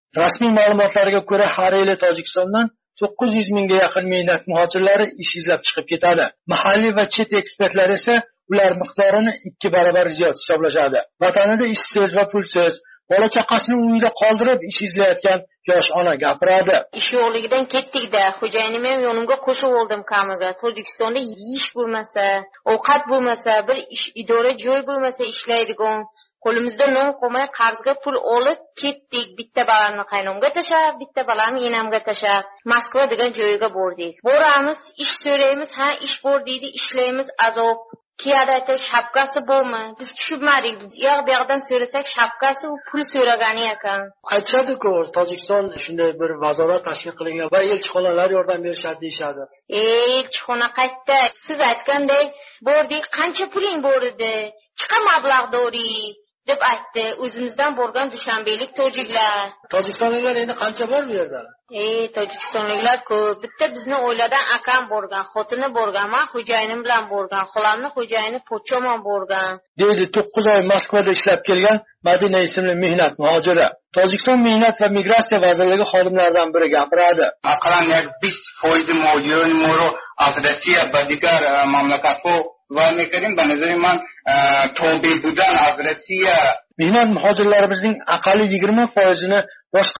Xo'janddan lavha